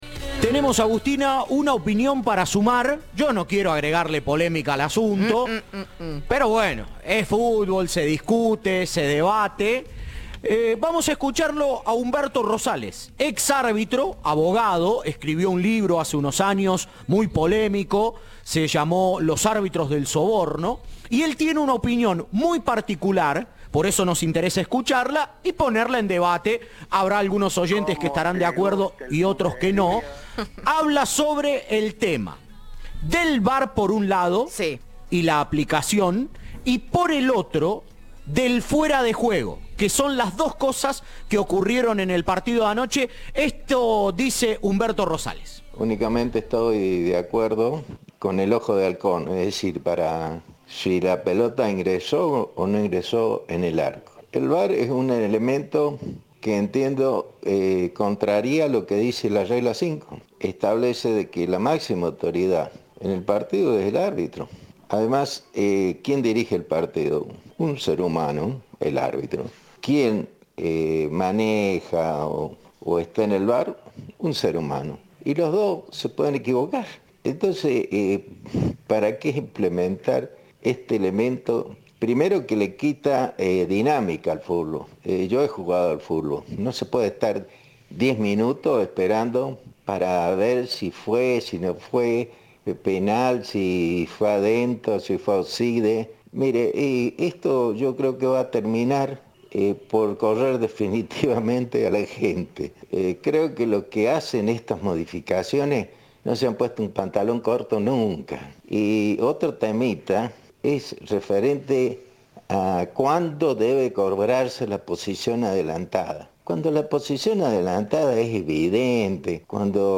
"Estoy de acuerdo con el ojo de halcón, para determinar si ingresó (la pelota) o no en el arco", planteó en diálogo con Cadena 3.